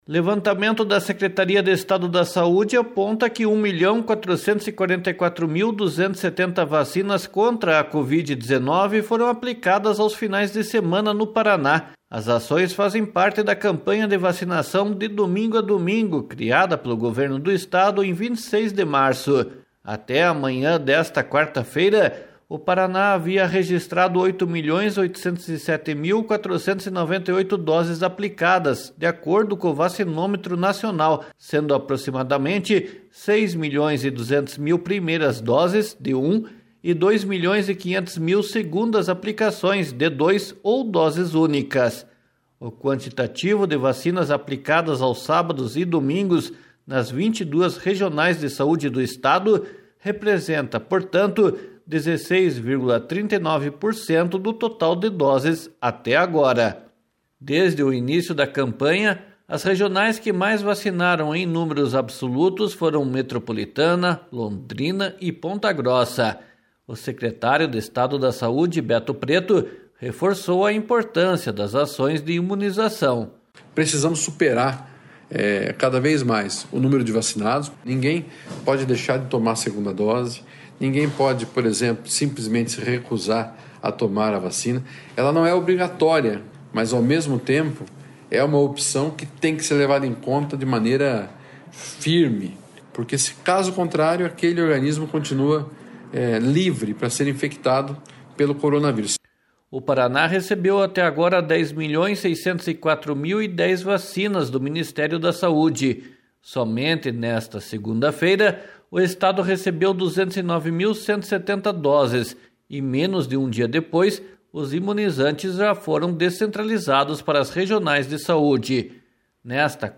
O secretário de Estado da Saúde, Beto Preto, reforçou a importância das ações de imunização. //SONORA BETO PRETO//